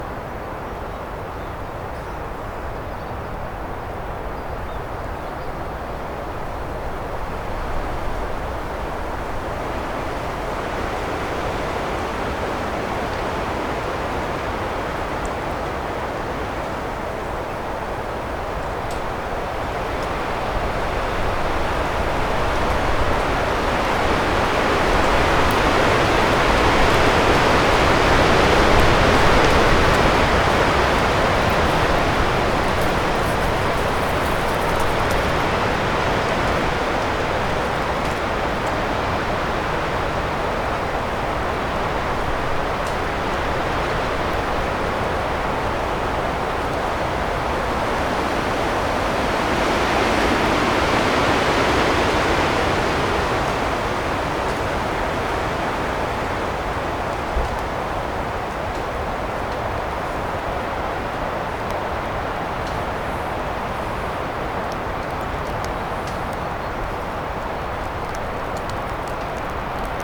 wind-1.ogg